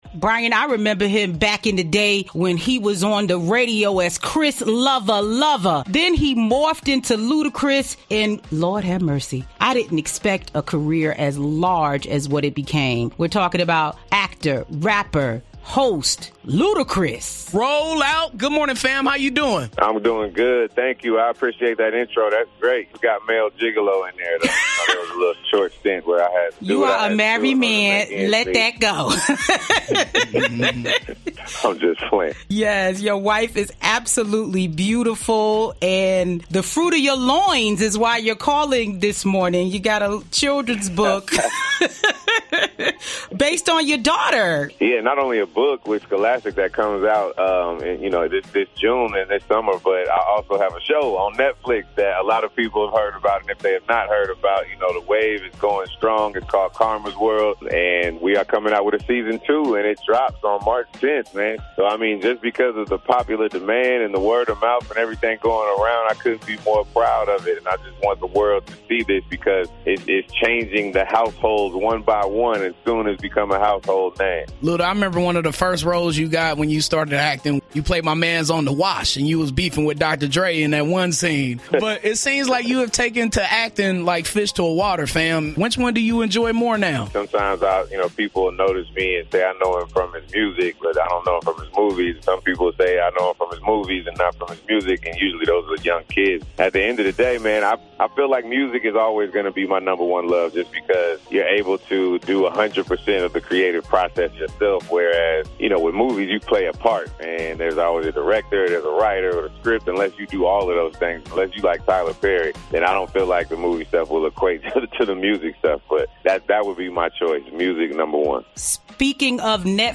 ludacrisinterview.mp3